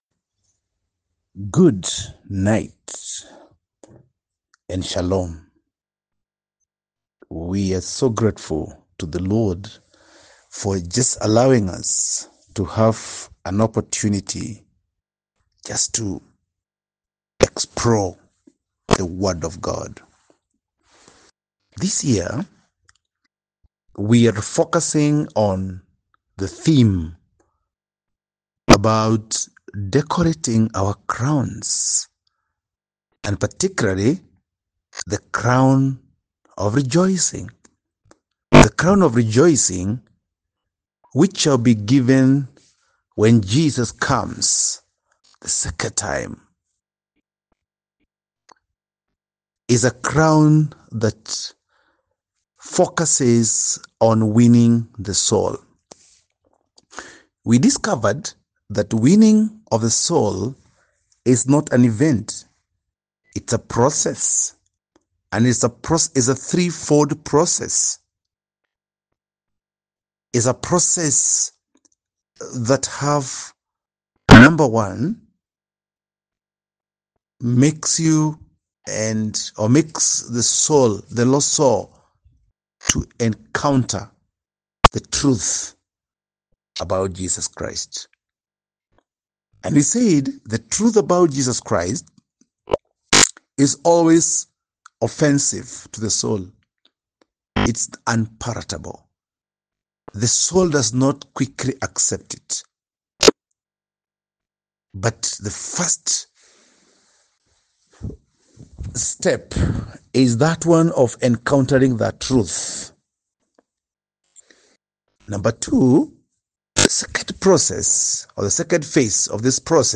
Post Lesson Teaching Summary